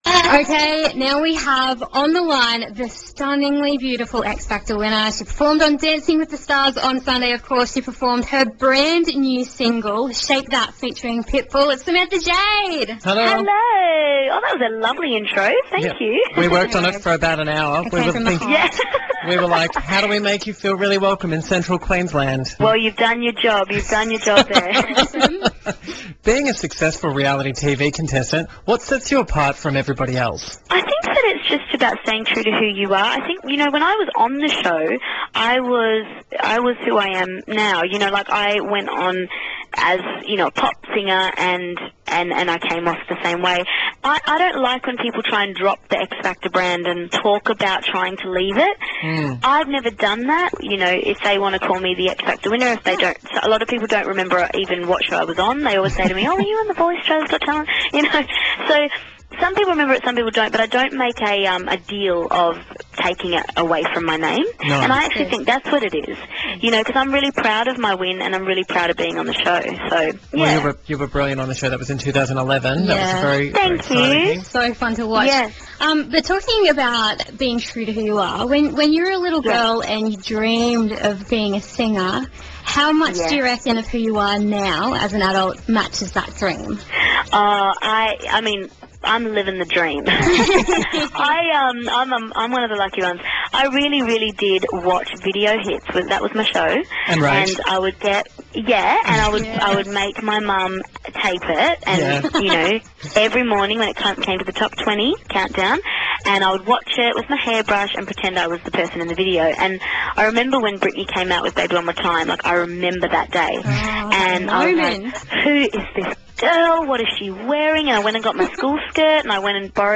Samantha Jade Interview